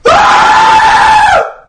Scream Cry Of Fear Sound Button - Free Download & Play
Games Soundboard274 views